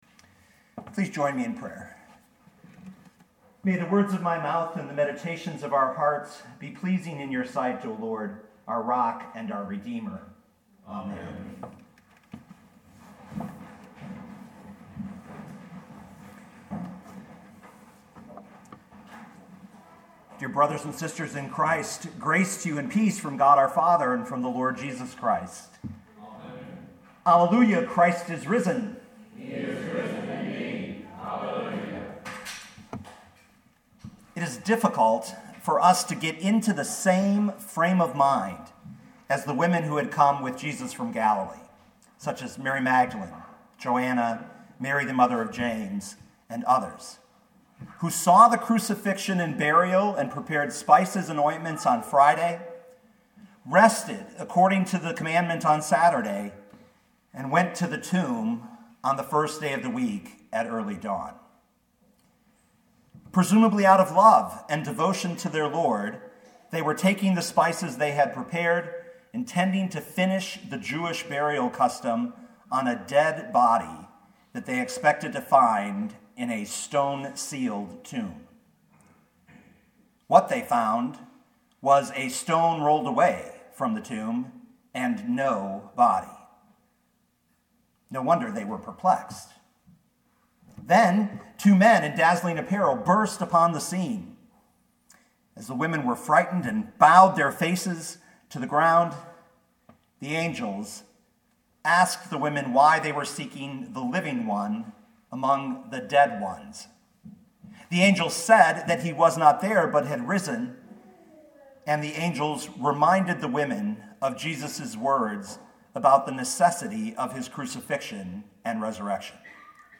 2019 Luke 24:1-12 Listen to the sermon with the player below, or, download the audio.